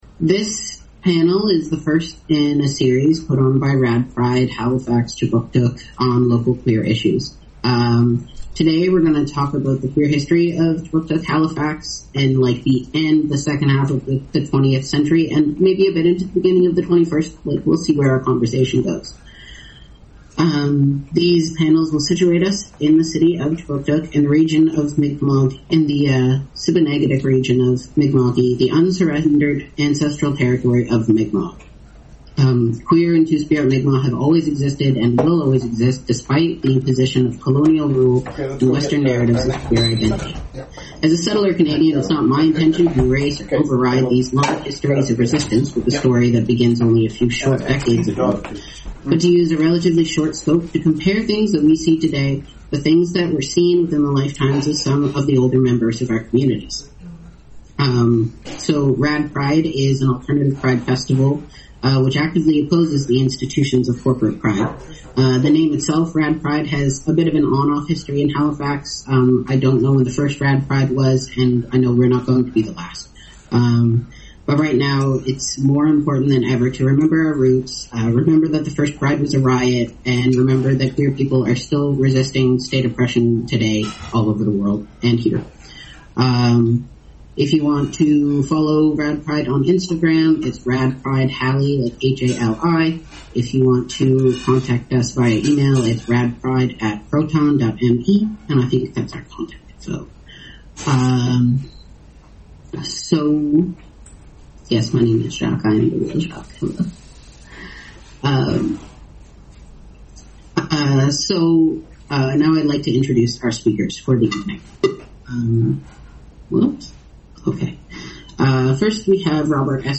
Session 1: 2025-07-08: Local Queer History Zoom event This evening's panel will be primarily Q&A style. After a brief introduction, our panelists will give us a bit of an overview on their part in the queer activist scene, after which we will move on to some questions our moderators have prepared for the evening. Once those questions have been answered we will open the floor to attendees.
About 20 guests dropped in and out during the 2-hour session.